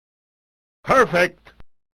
fighter game Street video sound effect free sound royalty free Gaming